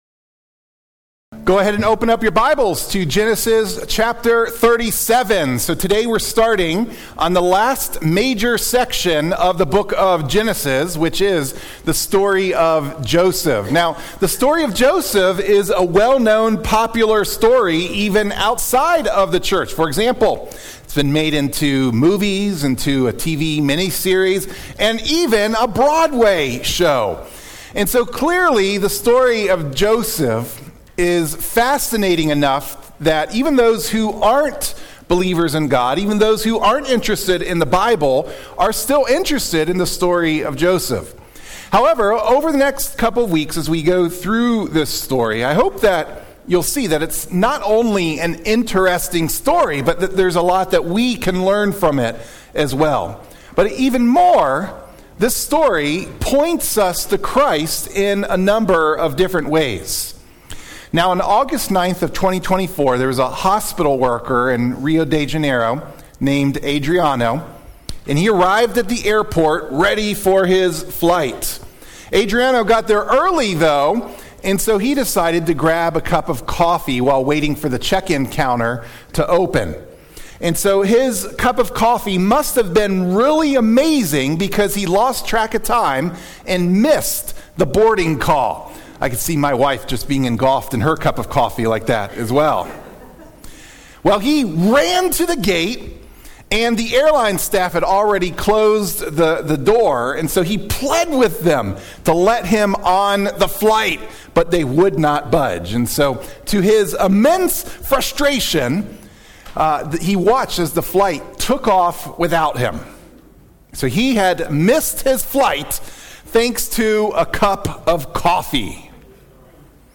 Sermon-5-4-25-MP3-for-Audio-Podcasting.mp3